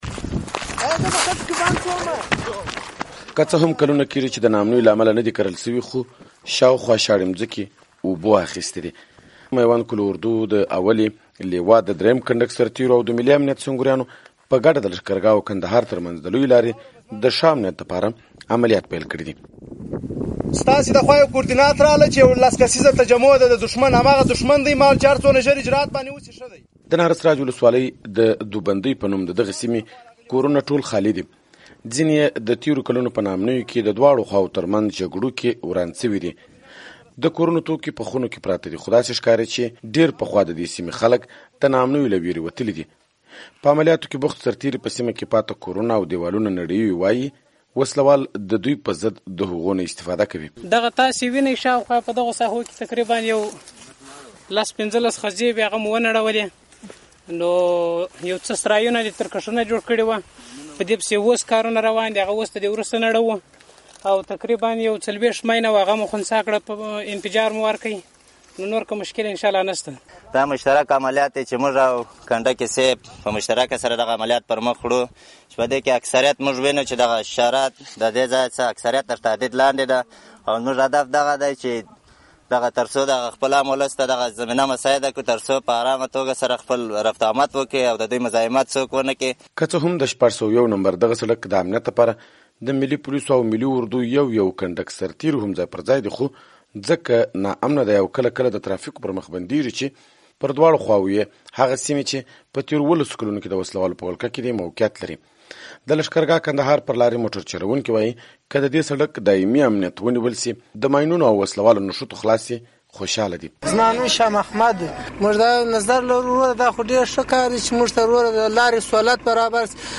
د هلمند راپور